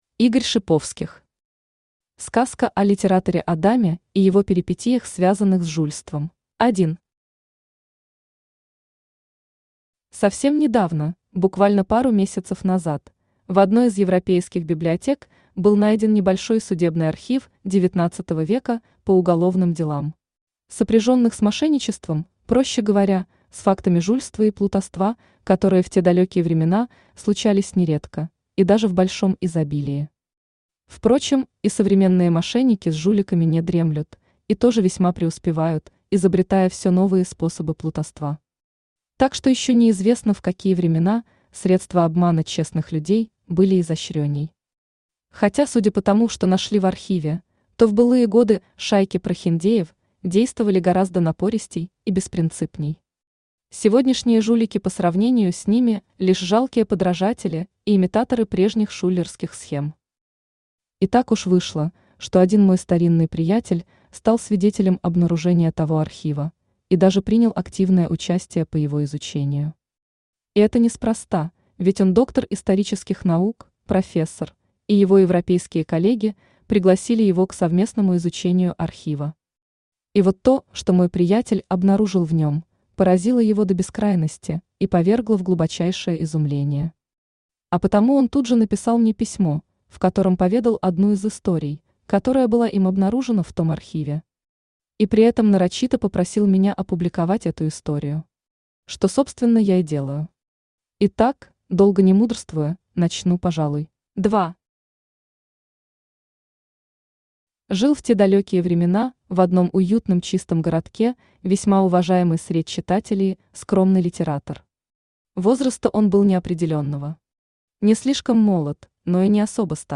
Аудиокнига Сказка о литераторе Адаме и его перипетиях связанных с жульством | Библиотека аудиокниг
Aудиокнига Сказка о литераторе Адаме и его перипетиях связанных с жульством Автор Игорь Дасиевич Шиповских Читает аудиокнигу Авточтец ЛитРес.